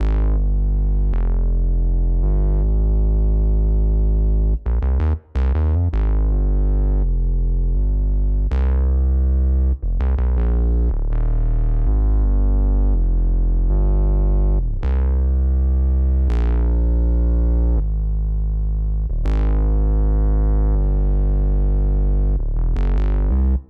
11 bass C.wav